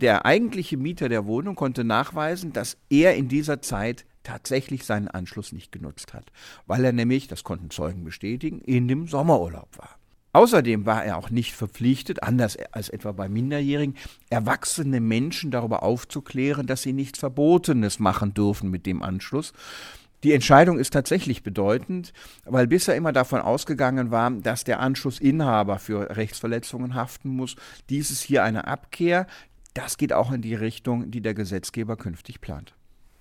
O-Ton: Keine Haftung des Mieters für seinen Untermieter bei illegalen Downloads
O-Töne / Radiobeiträge, Ratgeber, Recht, , , , , , ,